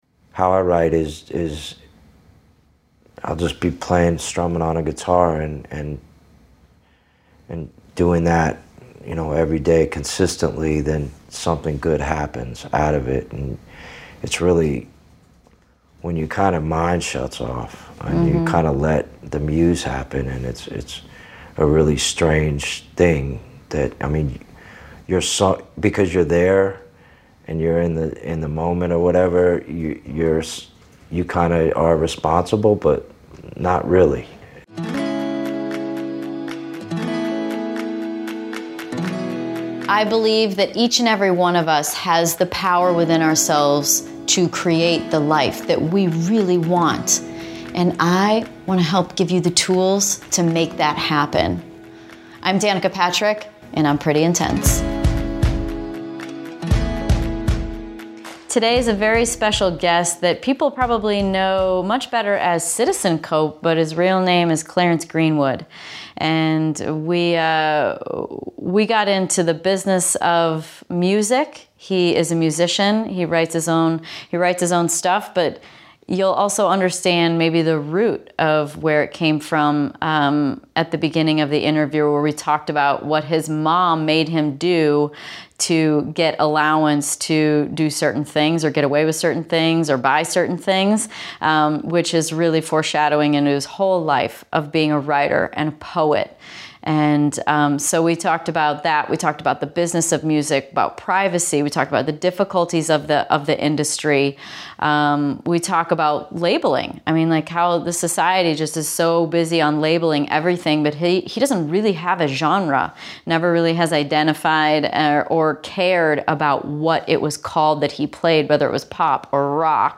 At the beginning of the interview, we talked about what his mom made him do to get his allowance, to do certain things or get away with certain things or buy certain things, which is really foreshadowing his whole life of becoming a writer and poet. We talked about the business of music, about privacy.